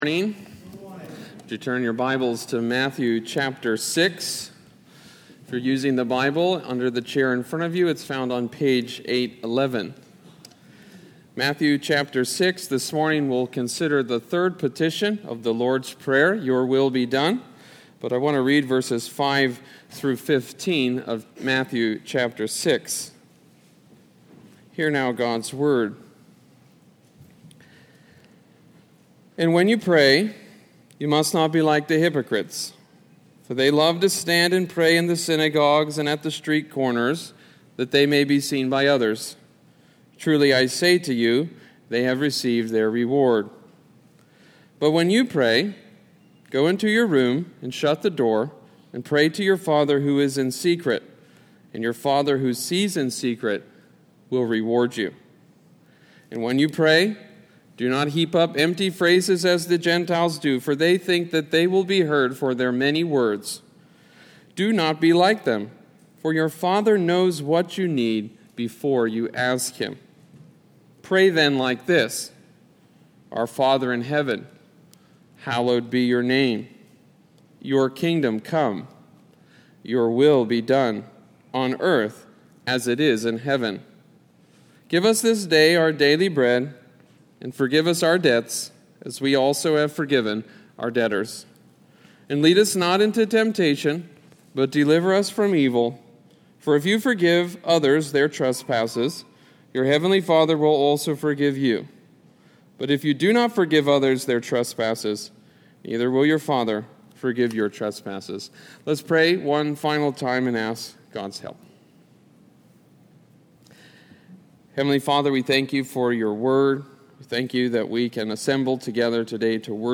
921-Sermon.mp3